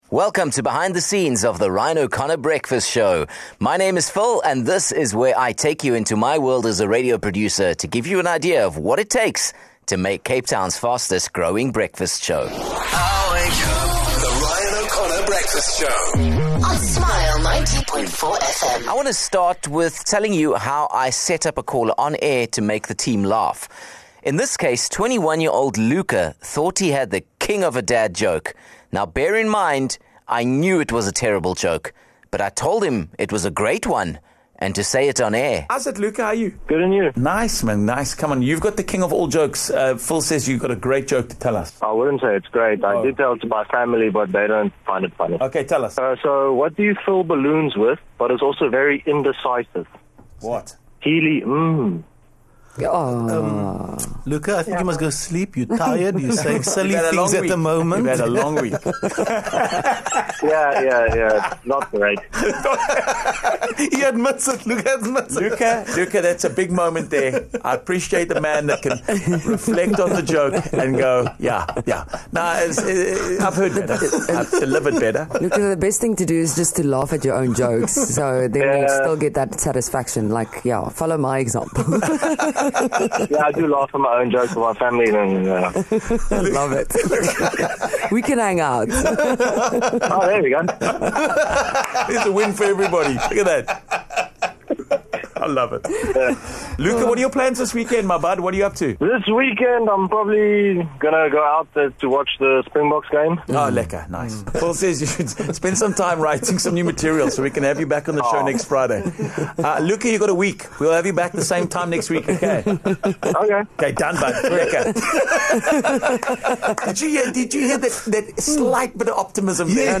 In this week's episode we listen back to some of the moments that made us laugh uncontrollably (because they were never meant to happen). Welcome to a blooper episode.